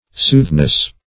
Soothness \Sooth"ness\, n. Truth; reality.